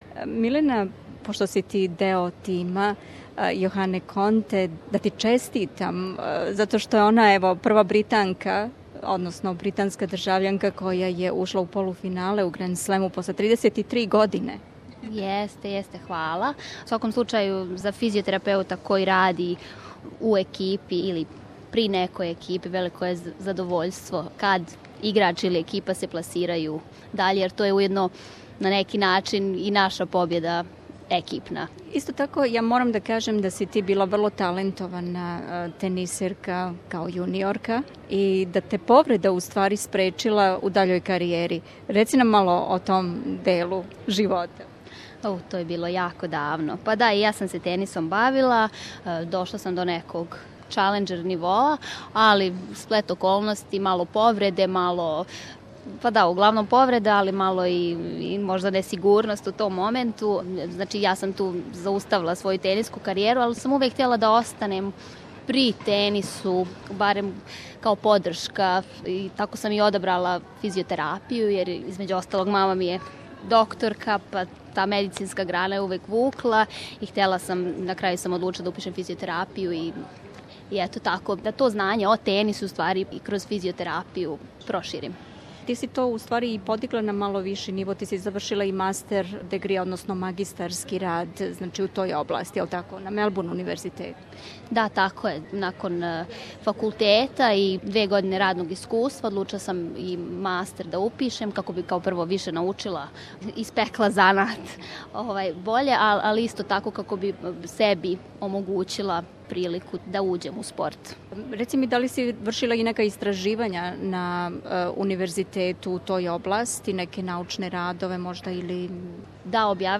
смо разговарали у Мелбурн парку, током Аустралиан опена.